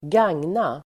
Uttal: [²gang:na]